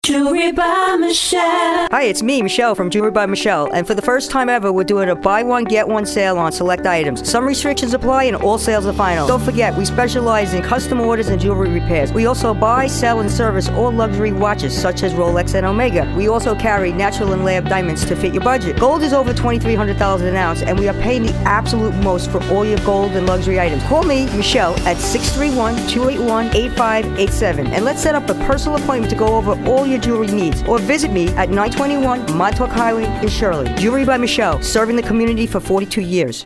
New Radio Ad for Jewelry by Michele
jewelry_by_michele_radio.mp3